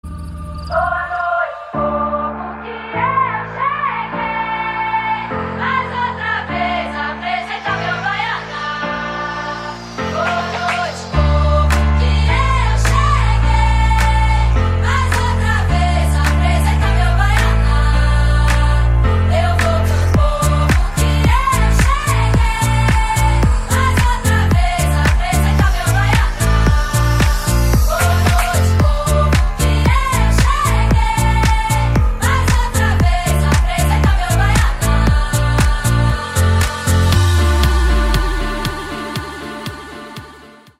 • Качество: 160, Stereo
deep house
заводные
dance
Electronic
этнические